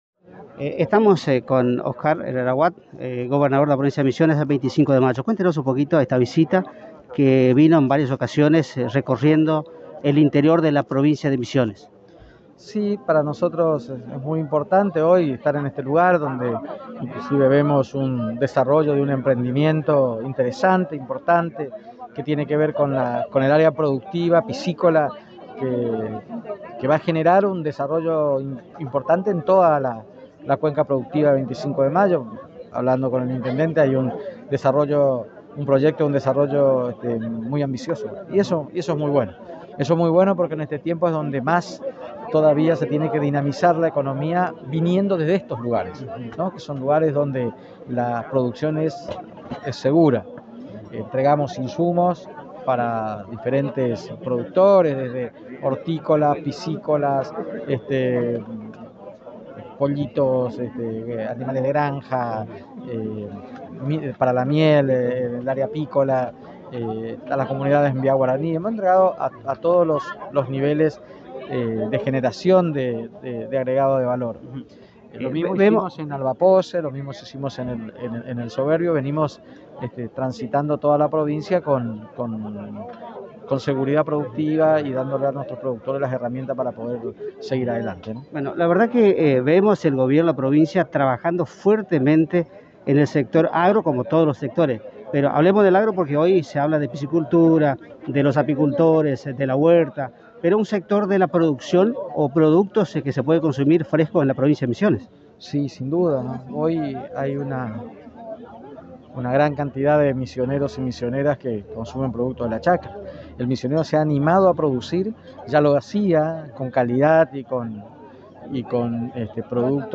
En diálogo exclusivo para la Agencia de Noticias Guacurarí comentó » Para nosotros es muy importante estar hoy en este lugar que inclusive vemos el desarrollo de un emprendimiento interesante y muy importante que tiene que ver con el área productiva piscícola, qué va a desarrollar una importante cuenca productiva en 25 Mayo.